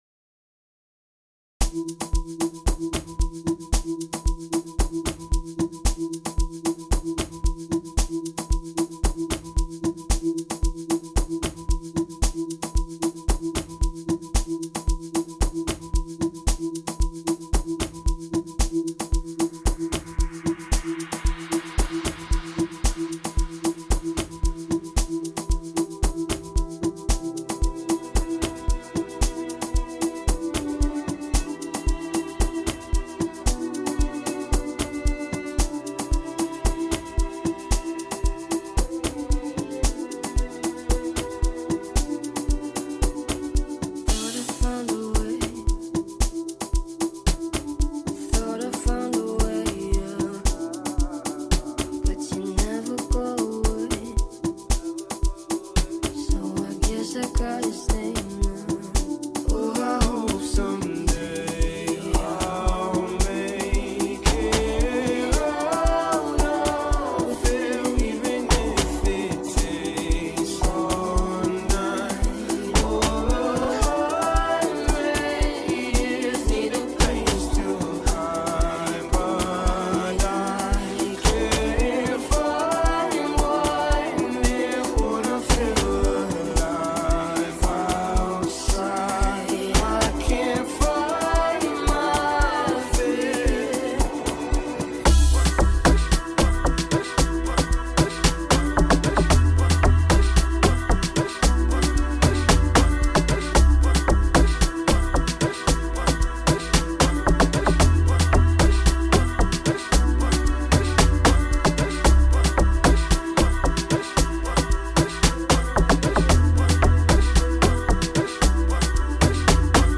In the heart of south-African contemporary beats